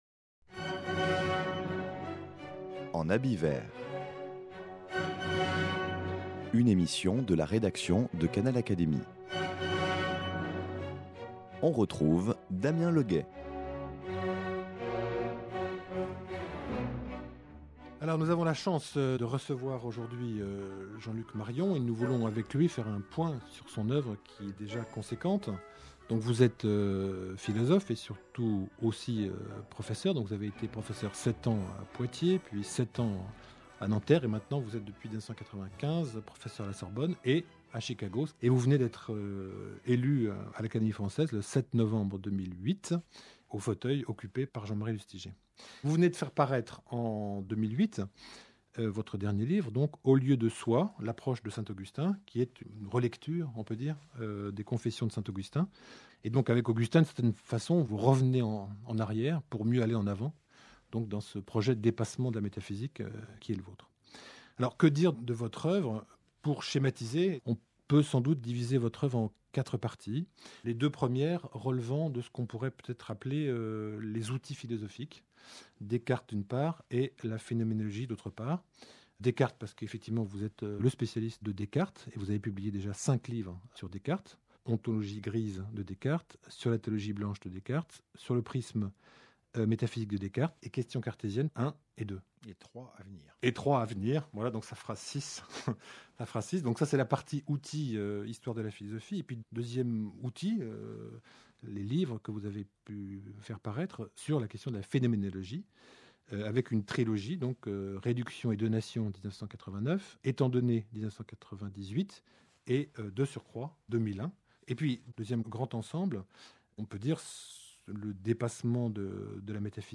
Mort d’une idole : Entretien avec Jean-Luc Marion, de l’Académie française